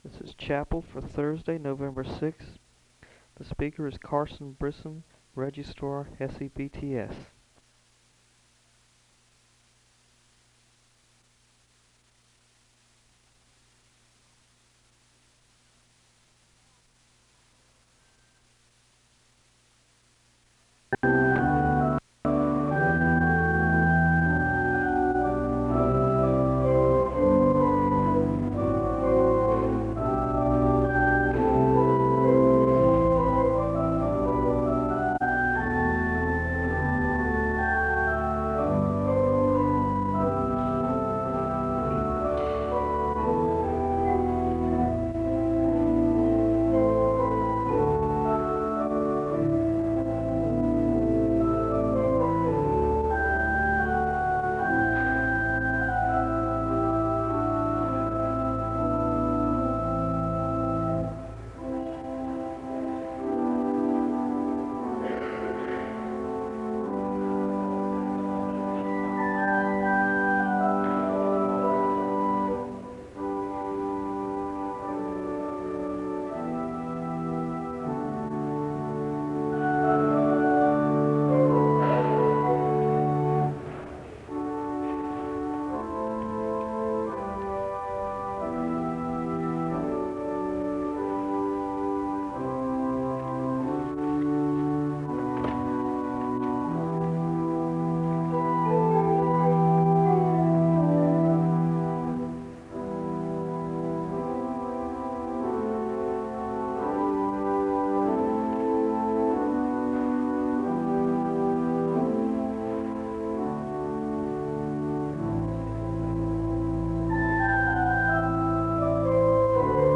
The service begins with organ music (0:00-3:27). There is a Scripture reading from Psalm 148 (3:28-5:42).
There is a liturgical reading (8:03-9:11). There is a moment of silence (9:12-10:27). The choir sings a song of worship (10:28-14:10).
The service closes with a benediction (24:35-24:56).